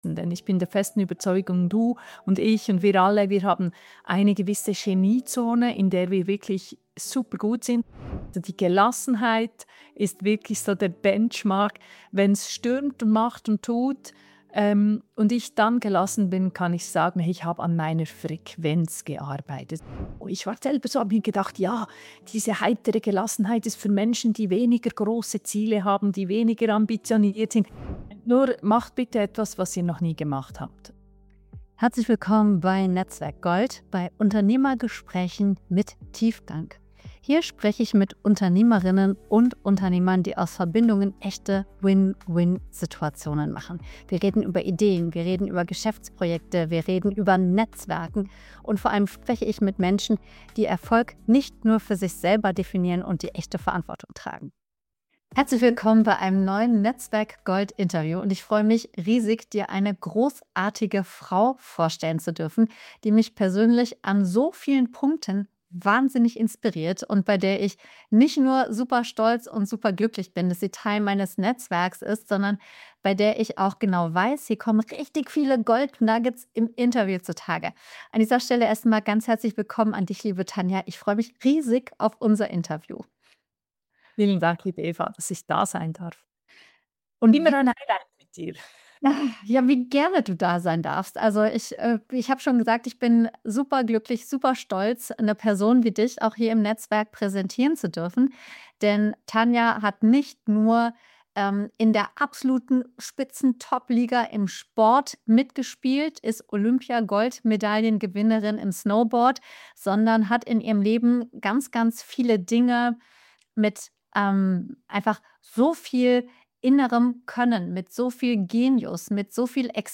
Im Interview spricht sie über Sabotage-Storys, die Geniezone, die sich nie anlernen lässt – und warum Pause machen allein noch keinen Burnout heilt.